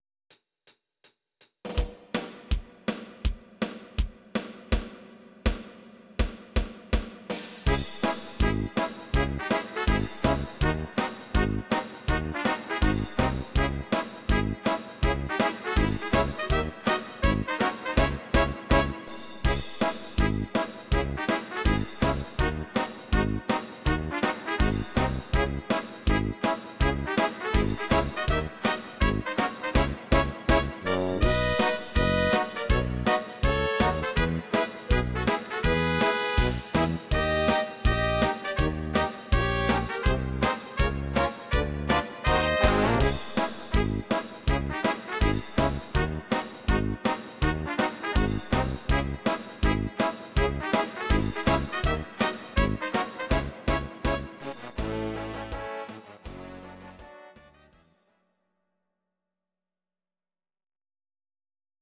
These are MP3 versions of our MIDI file catalogue.
Please note: no vocals and no karaoke included.
instr. trumpet